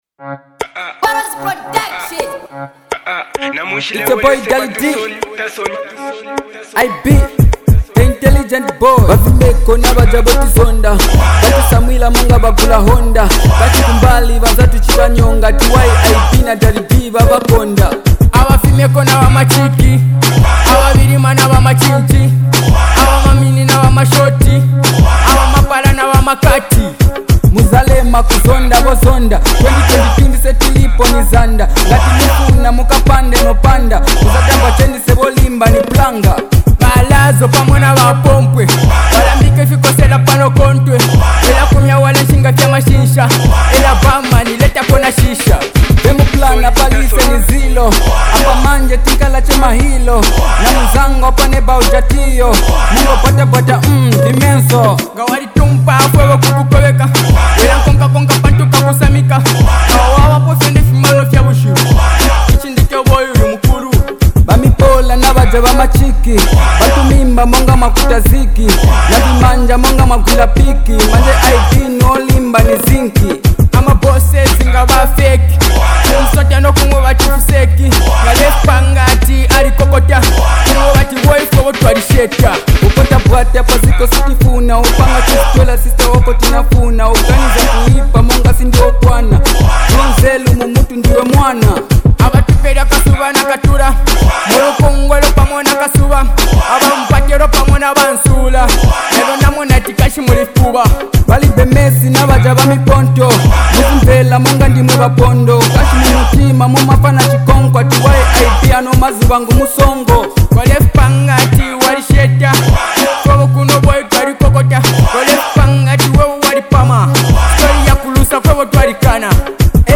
Genre: African Music